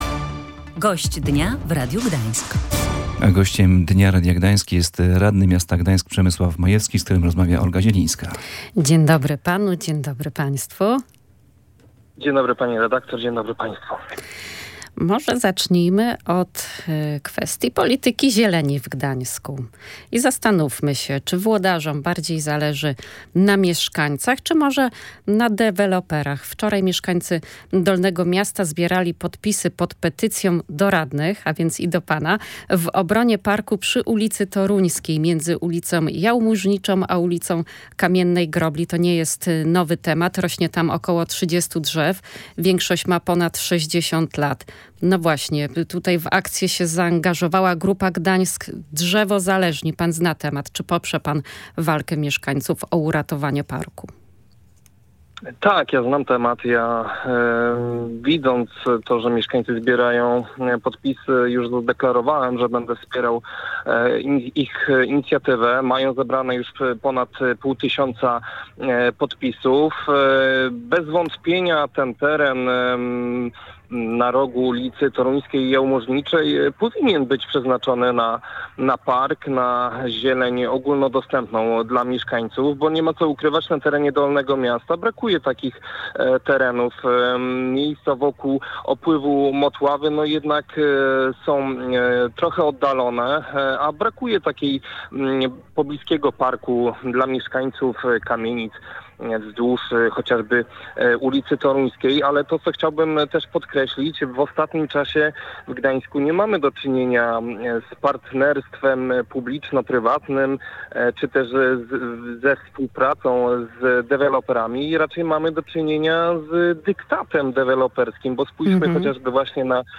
Gościa Dnia Radia Gdańsk, którym był Przemysław Majewski, radny Prawa i Sprawiedliwości.